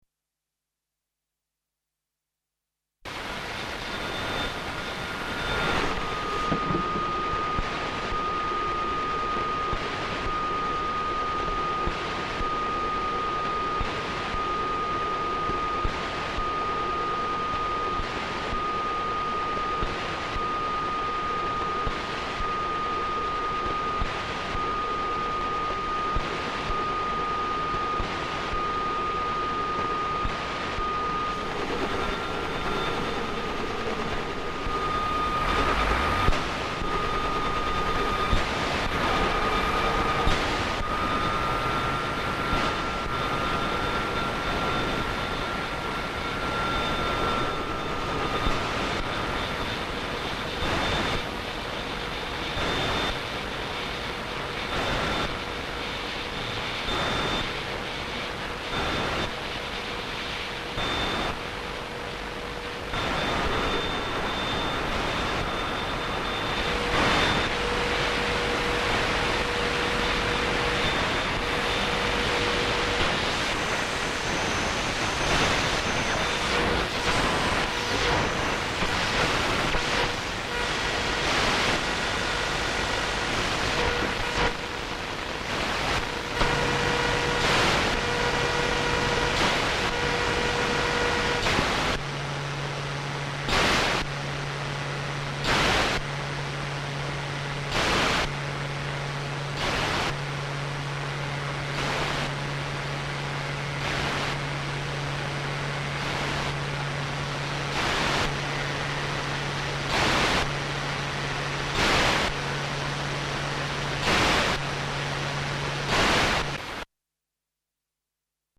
MZ-R70/CFM-10 Abbildung eines Minidisk Recorders MZ-R70 und eines Radios CFM-10 auf Minidisk.